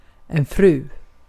Ääntäminen
IPA : /ˈmɪs.ɪz/